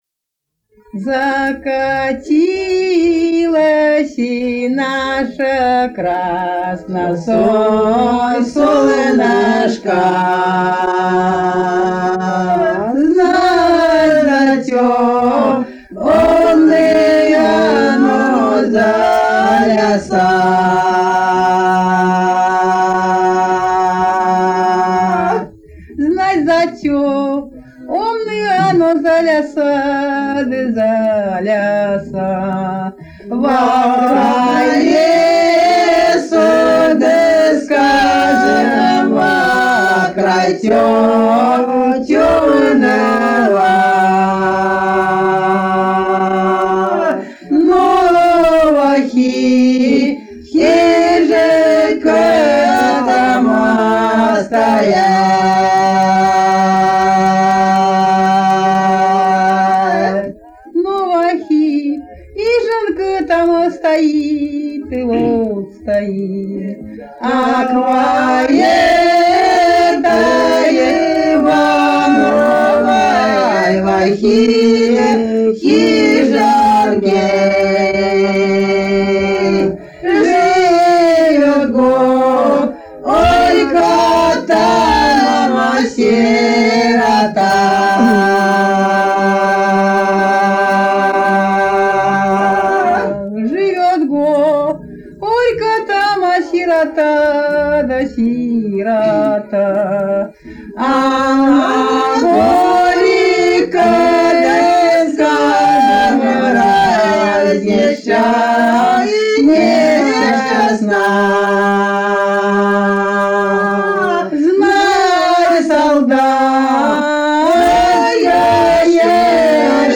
10_-_10_Закатиласи_наша_красно_солнушка,_лирическая.mp3